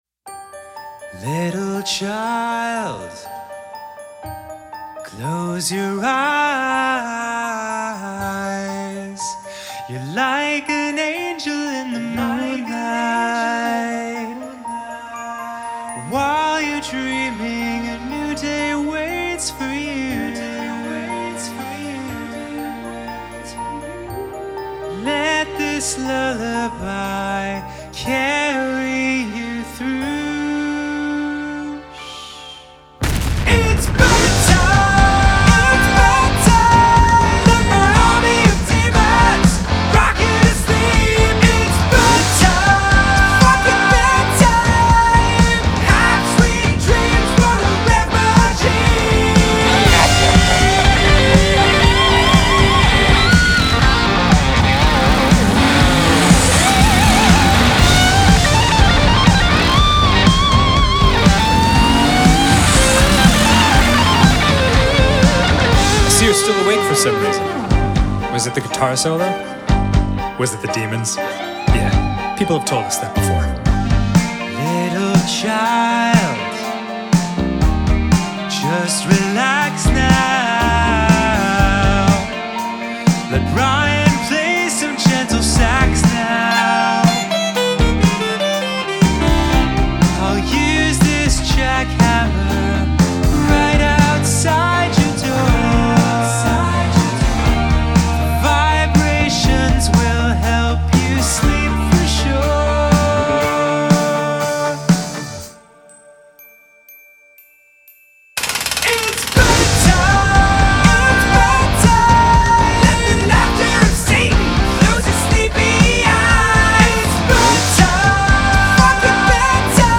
BPM121-121
Audio QualityPerfect (High Quality)
Comedy Rock song for StepMania, ITGmania, Project Outfox
Full Length Song (not arcade length cut)